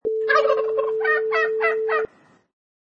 Wav files: Turkey Gobble and Squawking 1
Turkey gobble with squawking
Product Info: 48k 24bit Stereo
Category: Animals / Birds
Try preview above (pink tone added for copyright).
Turkey_Gobble_and_Squawking_1.mp3